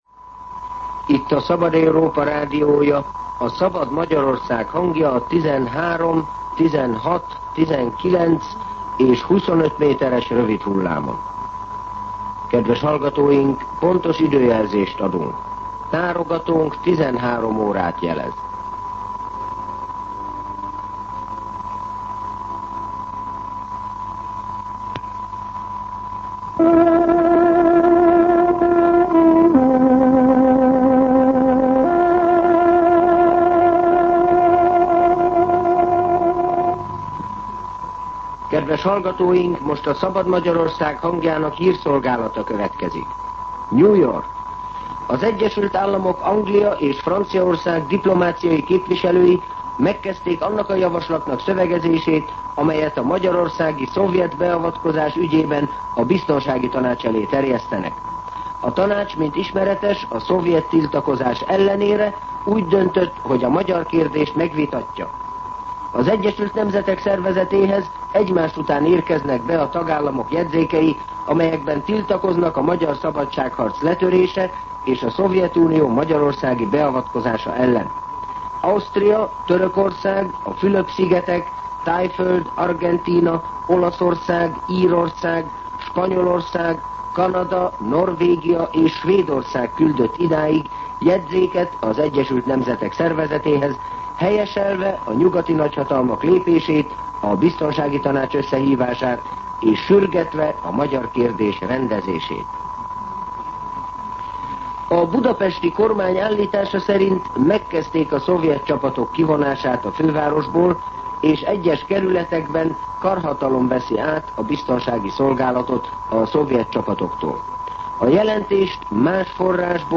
13:00 óra. Hírszolgálat